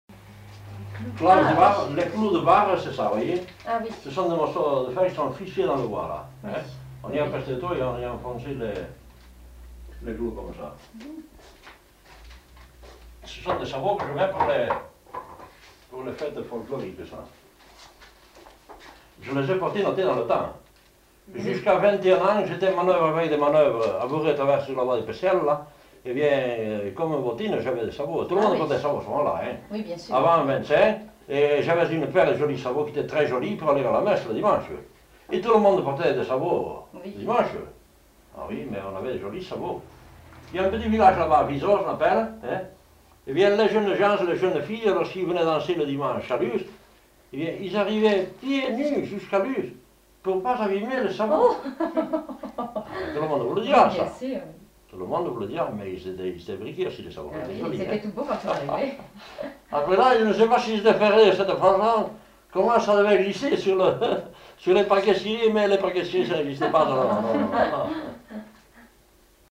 Aire culturelle : Bigorre
Genre : récit de vie